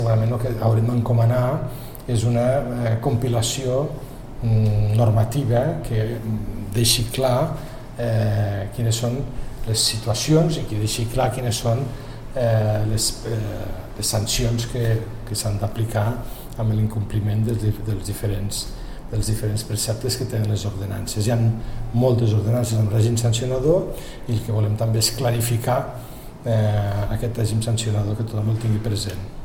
Tall de veu de l'alcalde, Fèlix Larrosa, sobre la reunió d'aquest dimecres a Paeria on l'equip de govern ha proposat la creació d’un cos d’inspecció per garantir el bon ús de l’espai públic.